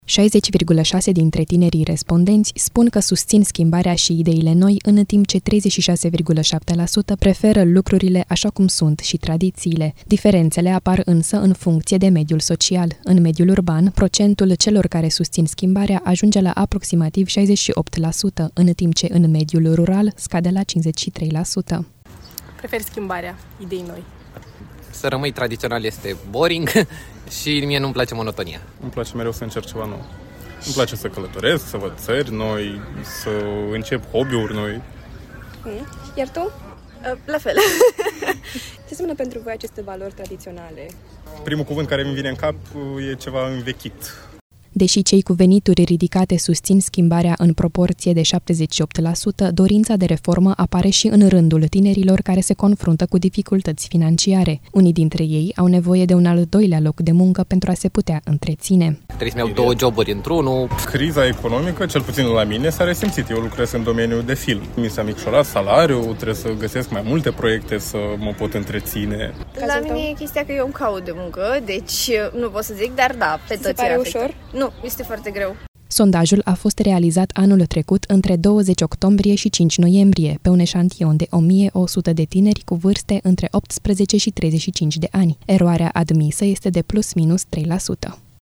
„Prefer schimbarea, idei noi”, consideră o fată.
„Să rămâi tradiționalist e boring, iar mie nu-mi place monotonia”, este de părere un băiat.
„La mine e chestia că eu îmi caut de muncă. Este foarte greu”, spune o fată.